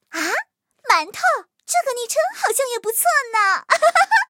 M4谢尔曼司令部语音2.OGG